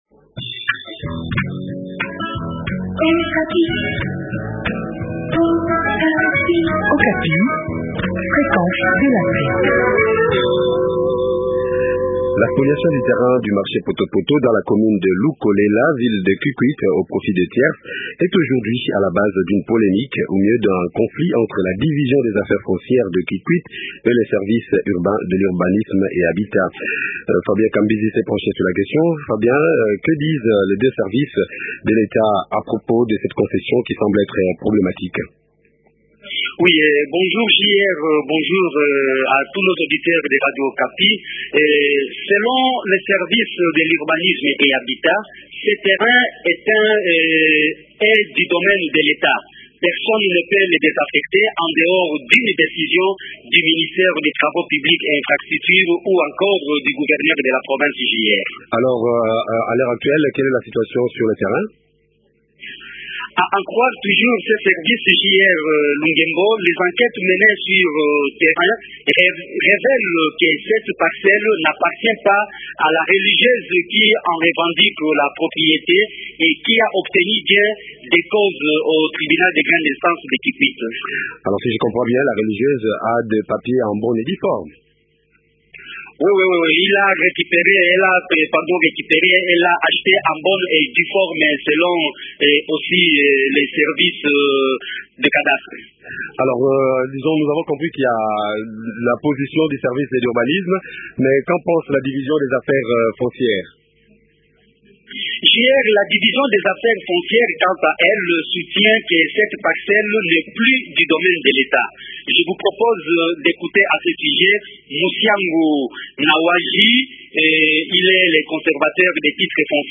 L’essentiel de son entretien